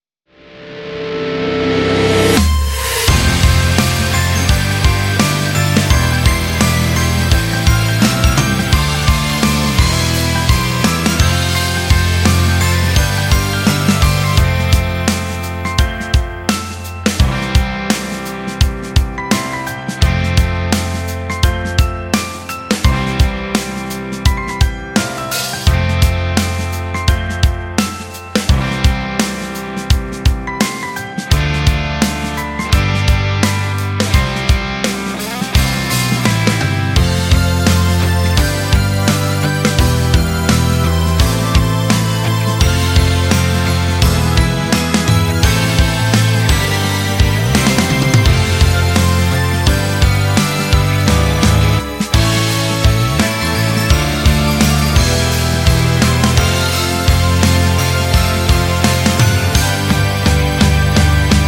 応募種別 ミックスチュアサウンドで沸いて魅せて聞かせる、エモーショナル系アイドルグループ
楽曲 ROCK＆POP